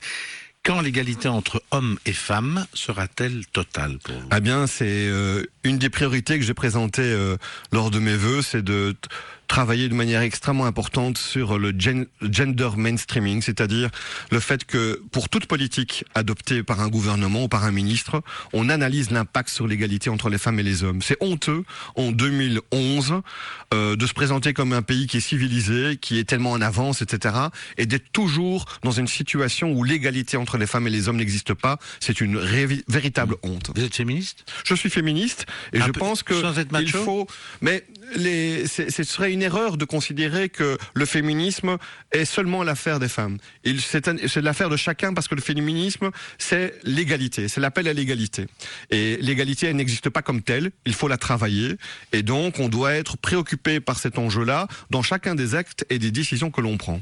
Voilà ce que j’avais déclaré lors d’un récent passage radio lors duquel j’avais pu évoquer l’égalité Hommes-Femmes, et que je vous propose de réécouter ici